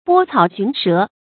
拨草寻蛇 bō cǎo xún shé
拨草寻蛇发音
成语注音 ㄅㄛ ㄘㄠˇ ㄒㄩㄣˊ ㄕㄜˊ